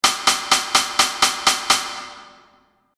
The drums join with sixteenths in the high hat.
ritme-drums.mp3